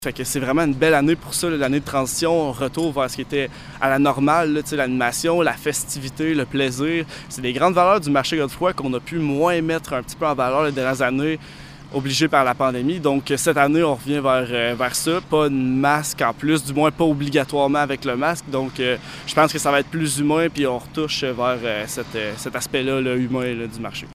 Après deux années de contraintes sanitaires, les dirigeants étaient tout sourire mercredi, lors d’une conférence de presse levant le voile sur cette édition où les visiteurs pourront à nouveau vivre pleinement l’expérience propre à un marché public.